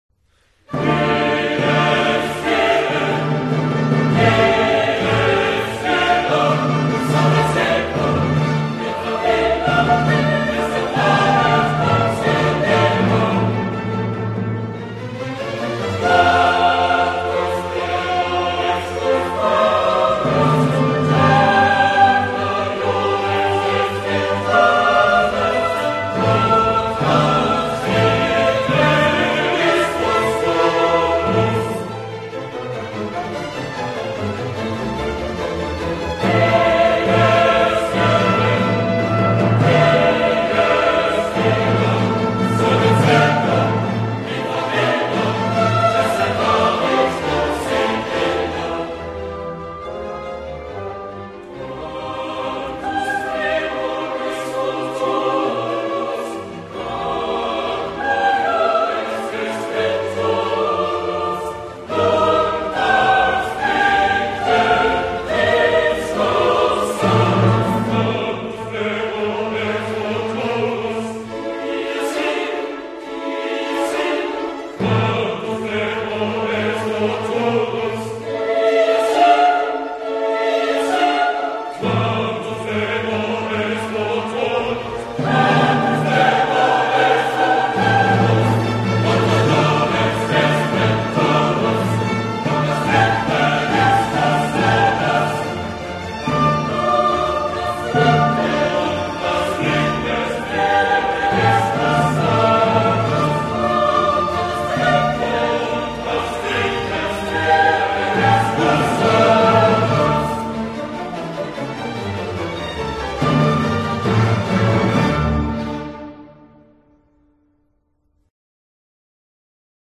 Orchestre philarmonique